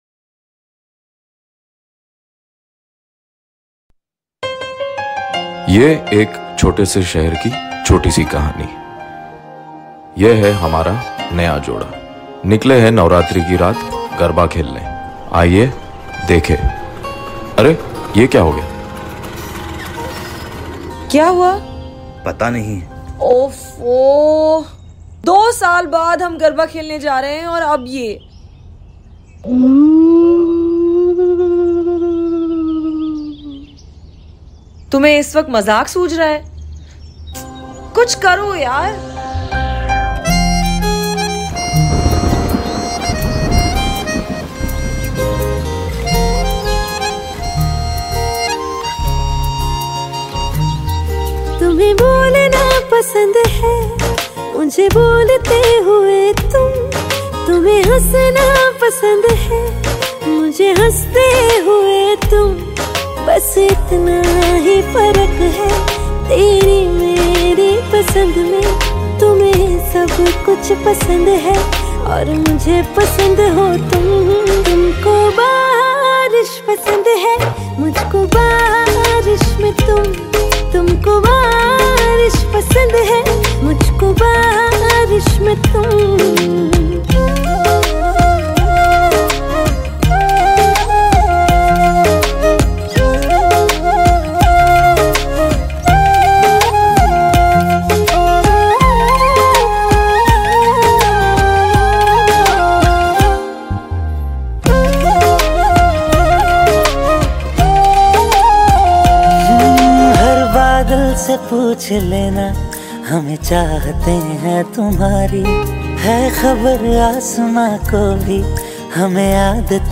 Categories Baarish Ringtones / Rain Ringtones